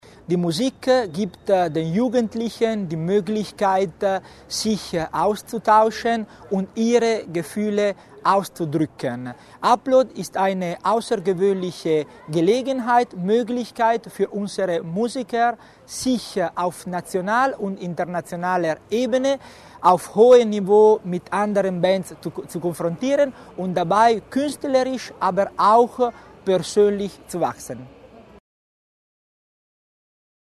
Landesrat Tommasini zur Bedeutung von Upload